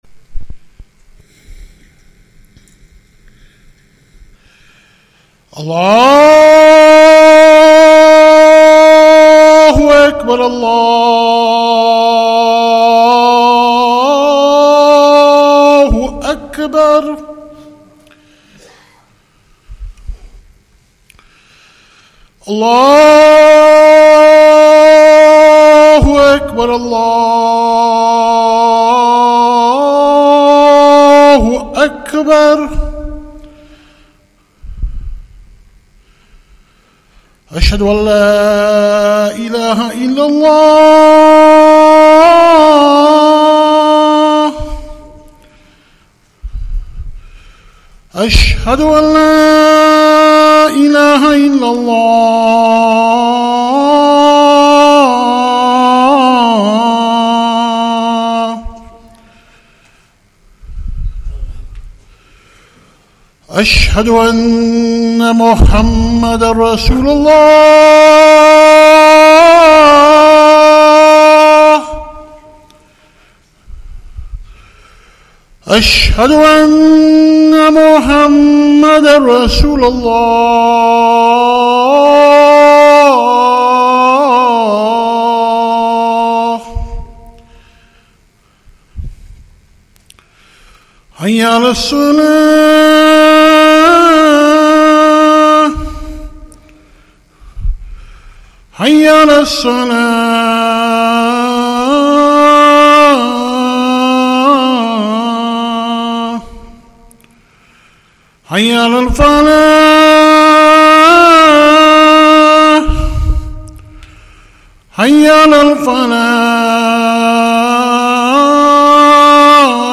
Jumuah
Masjid Al Farouq, Walsall